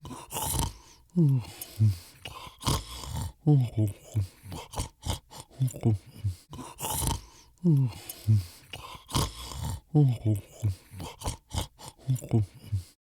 Cartoon Strange Snoring Sound Effect Free Download
Cartoon Strange Snoring